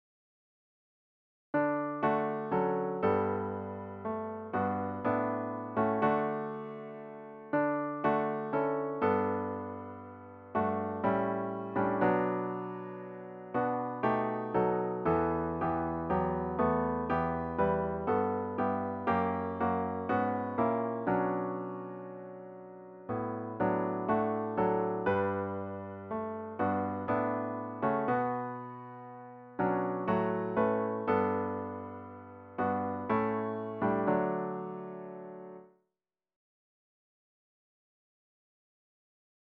About the Hymn
The hymn should be performed at a contented♩= ca. 60.